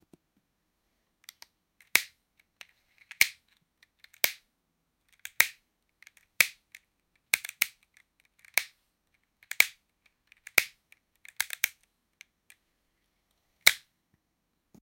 Childrens Sound Effects - Free AI Generator & Downloads
the-sound-of-lego-pieces-oxmmkx33.wav